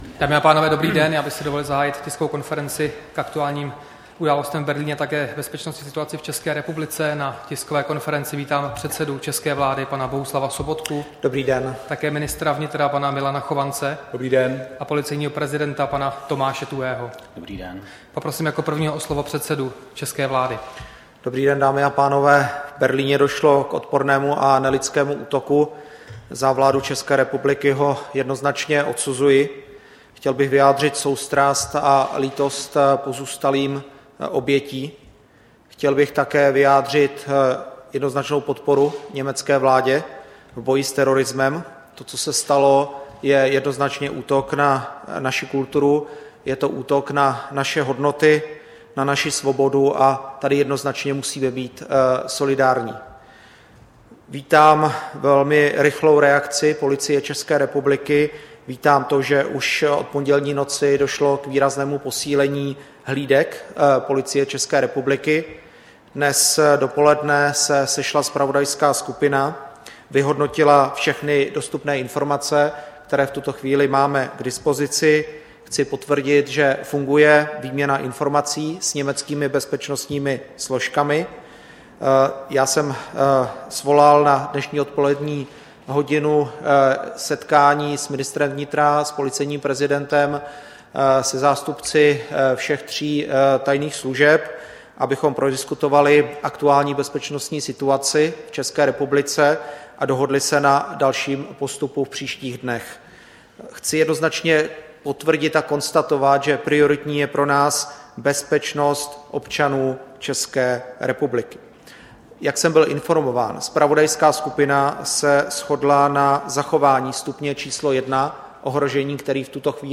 Tisková konference k aktuálním událostem v Berlíně a bezpečnostní situaci v ČR, 20. prosince 2016